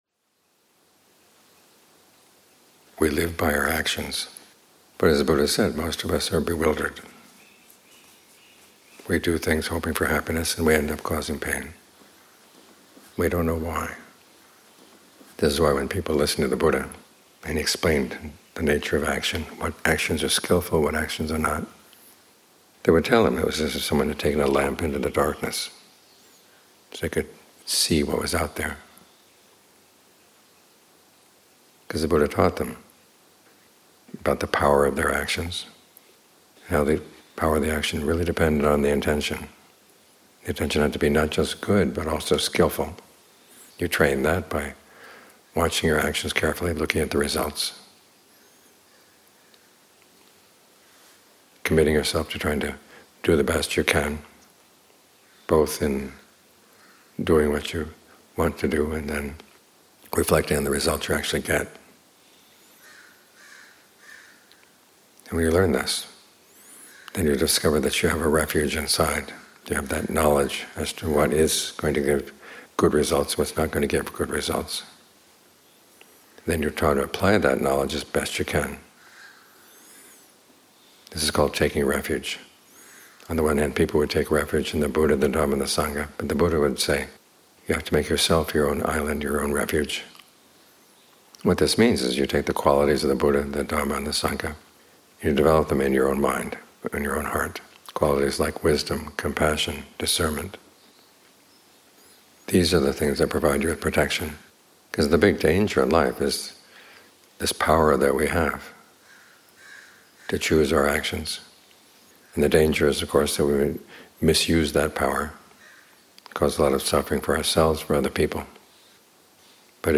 short morning talks